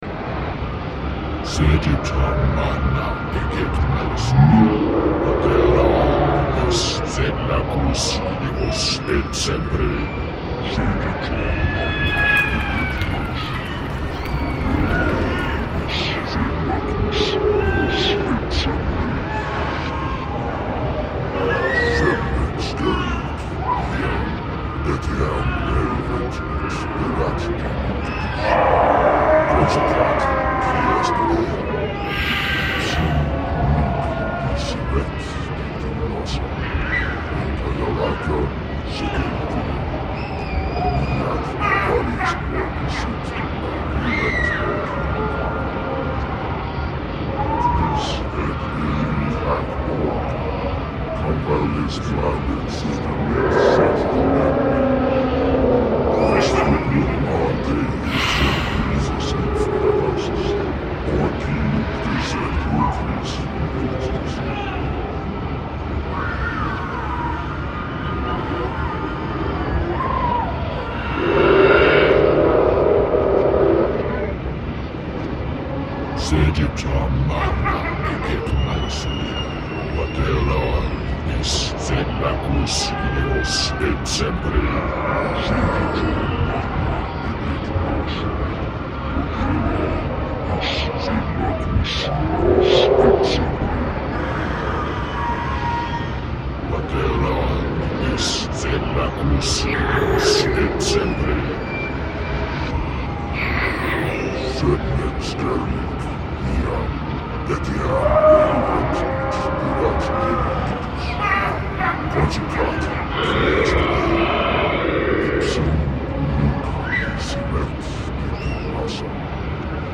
Звук из ада
• Категория: Звуки из ада
• Качество: Высокое